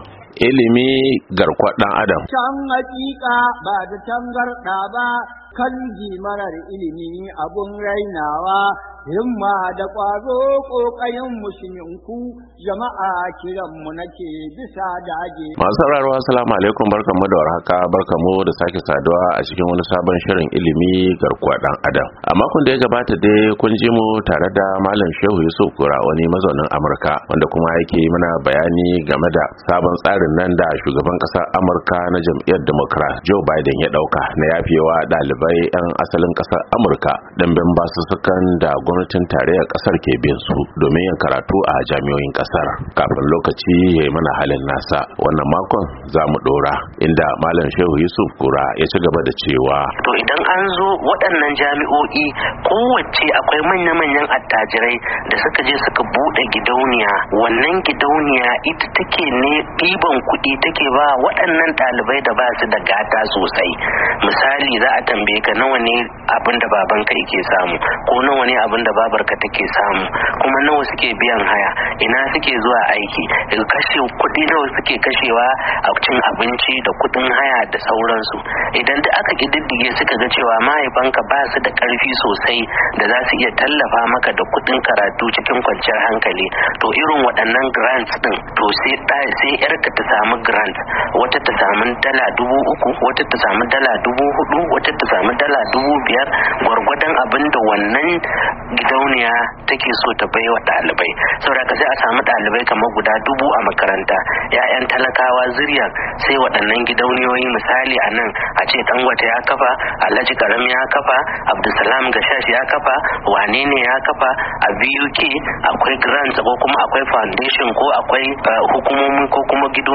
ILIMI GARKUWAN DAN ADAM: Tattaunawa Da Ba'amurke Ɗan Najeriya Kan Banbancin Tsarin Ilimin Amurka Da Najeriya-Nuwamba 7, 2022